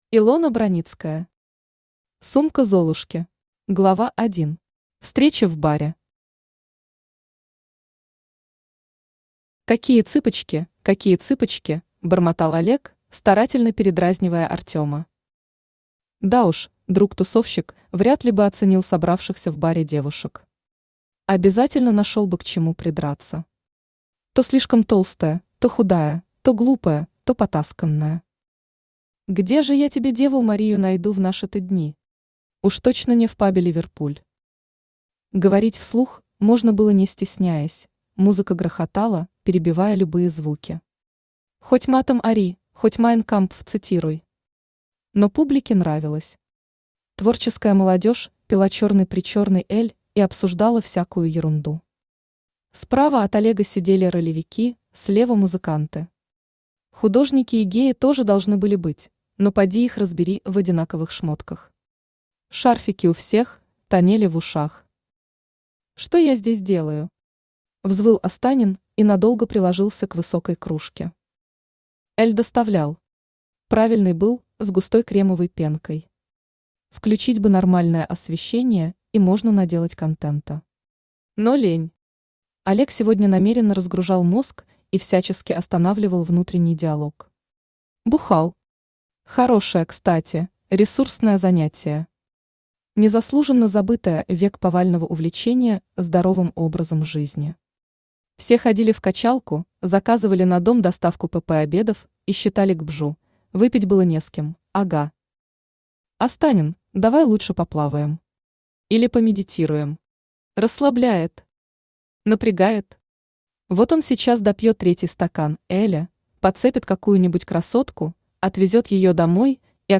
Аудиокнига Сумка Золушки | Библиотека аудиокниг
Aудиокнига Сумка Золушки Автор Илона Броницкая Читает аудиокнигу Авточтец ЛитРес.